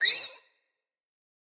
Sfx_creature_seamonkeybaby_hold_02.ogg